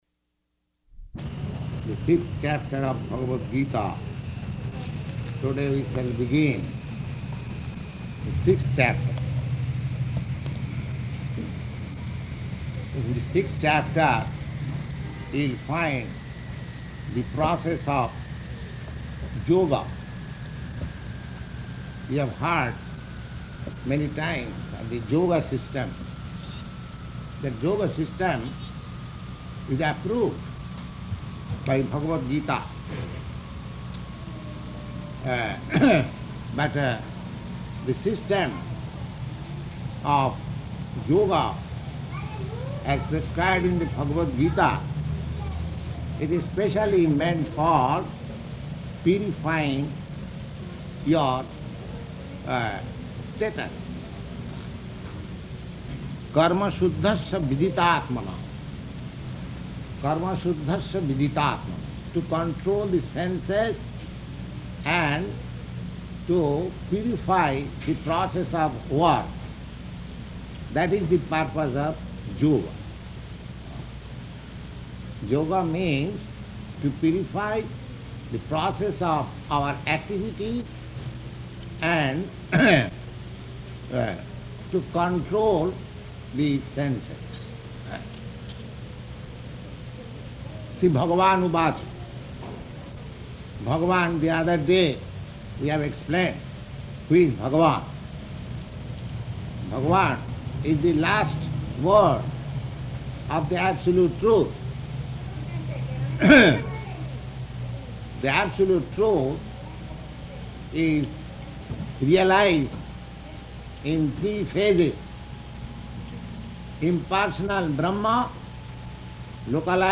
Bhagavad-gītā 6.1–4 --:-- --:-- Type: Bhagavad-gita Dated: September 2nd 1966 Location: New York Audio file: 660902BG-NEW_YORK.mp3 Prabhupāda: ...the Fifth Chapter of Bhagavad-gītā.